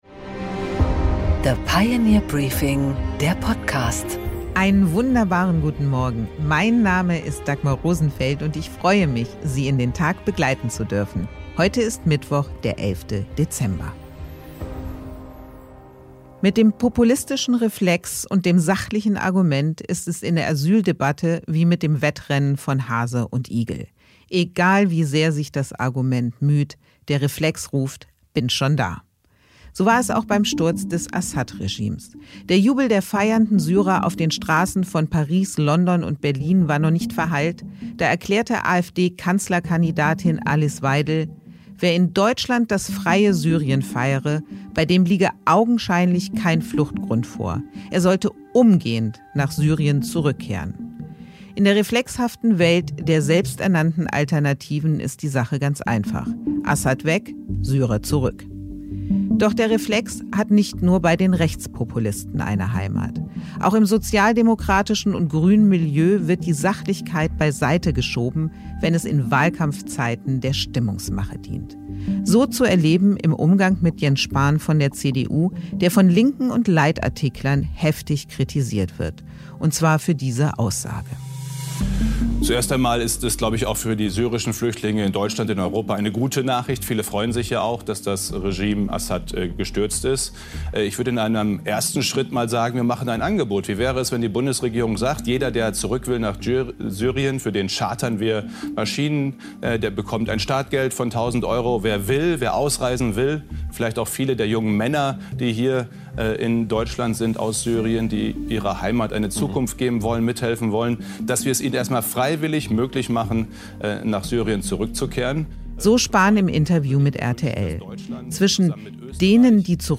Dagmar Rosenfeld präsentiert das Pioneer Briefing
Im Interview: Dietmar Bartsch, MdB Die Linke, spricht mit Dagmar Rosenfeld über schlechte Umfragewerte, das BSW und die “Mission Silberlocke”, die den Einzug in den Bundestag retten soll.